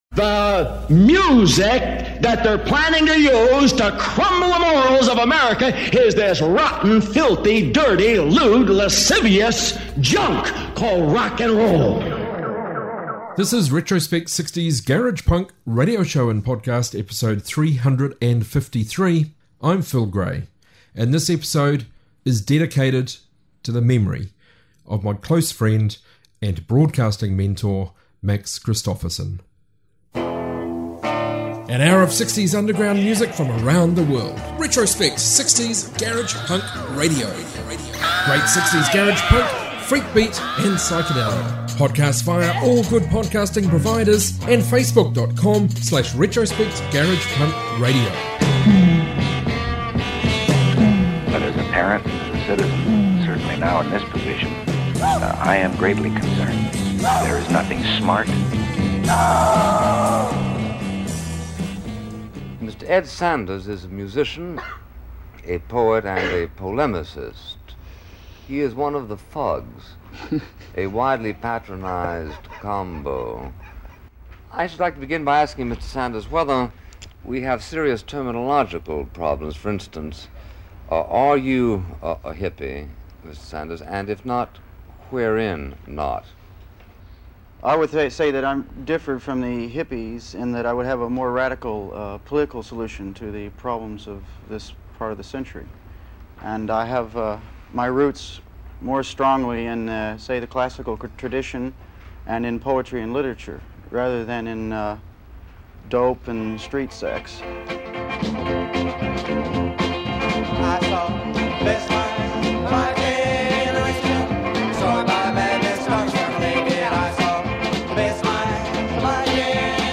60s global garage